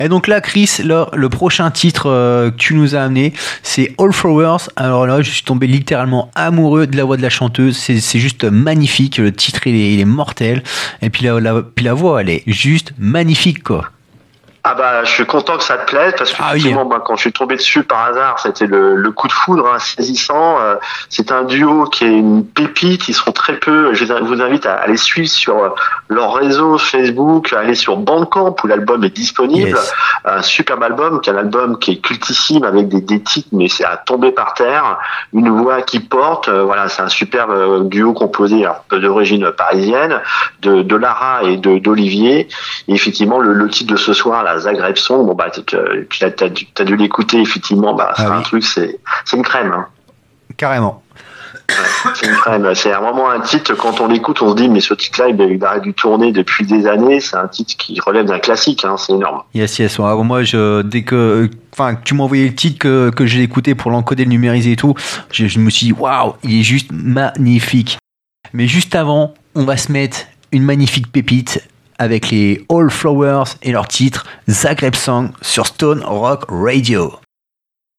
a vibrant hommage to the Alternative Rock of the 90’s